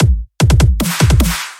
全力以赴的鼓声
Tag: 150 bpm Dubstep Loops Drum Loops 275.80 KB wav Key : Unknown